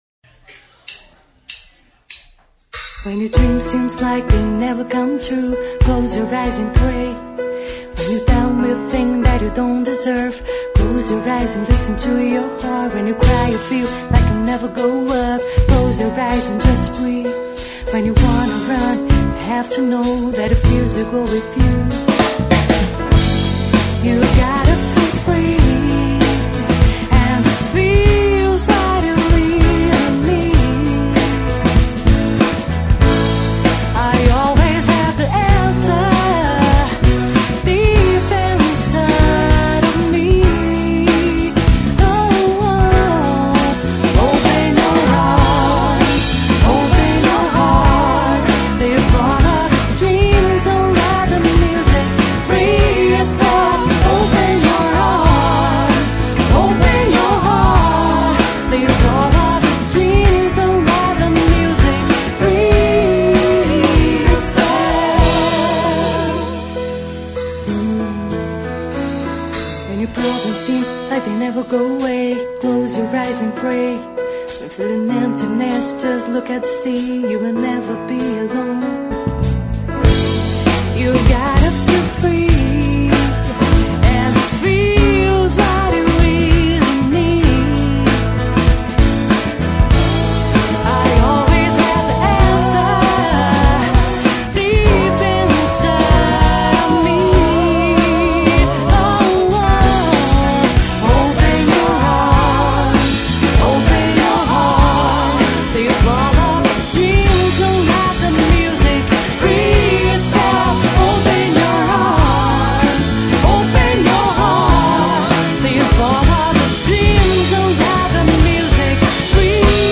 EstiloR&B